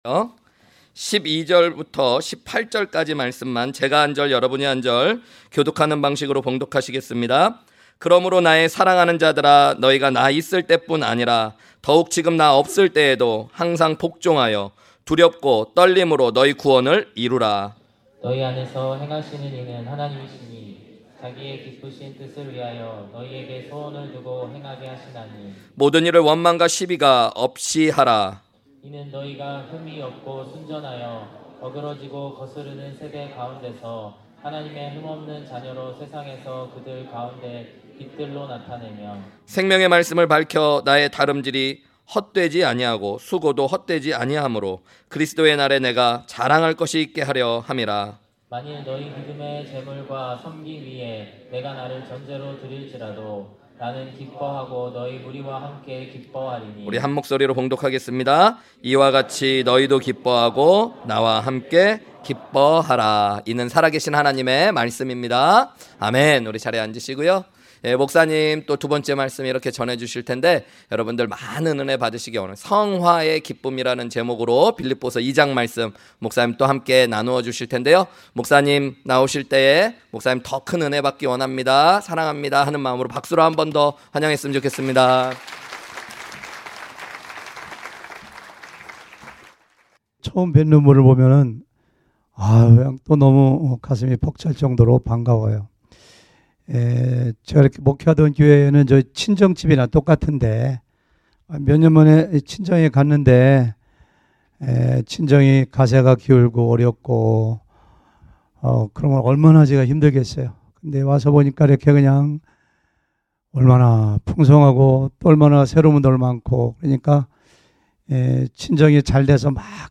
주일 외 설교
2022 H-Camp 첫째날 토요일 저녁 설교